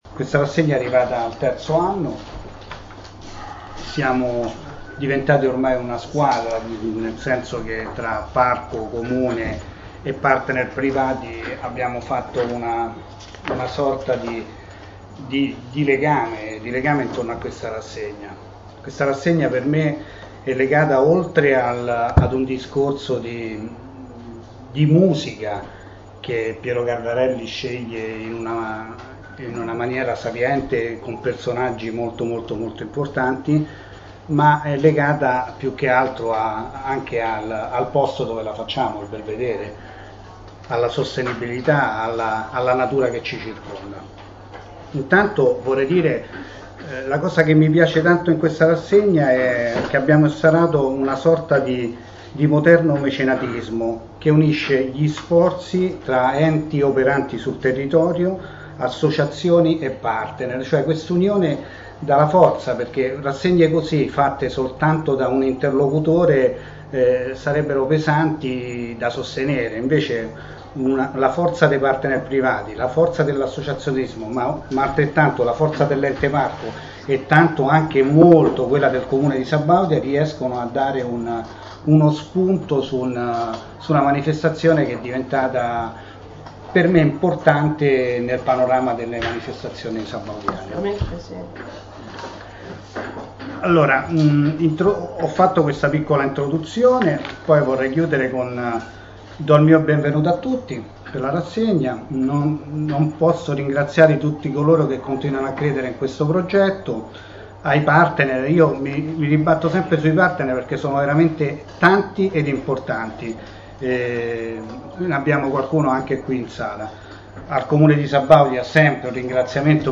Conferenza-stampa-I-Suoni-del-Lago.mp3